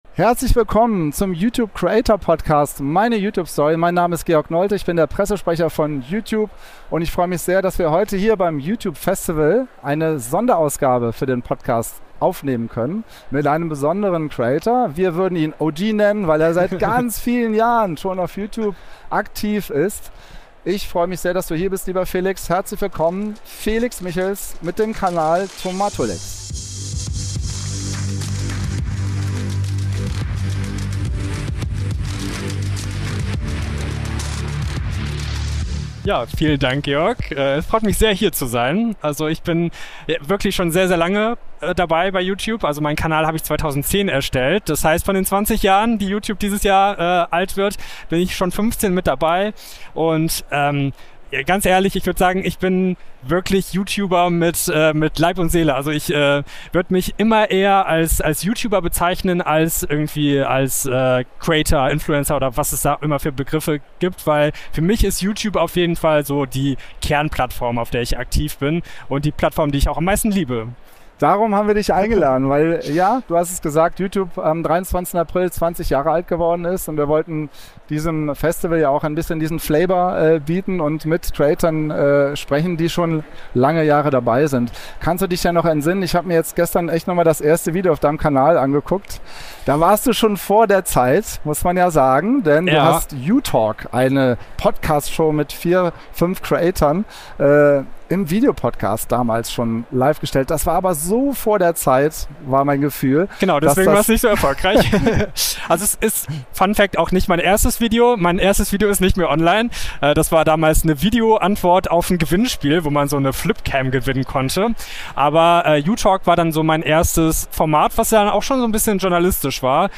Herzlich willkommen zu einer besonderen Ausgabe des YouTube Creator Podcasts "Meine YouTube Story", diesmal live produziert beim YouTube Festival in Berlin am 28. August 2025.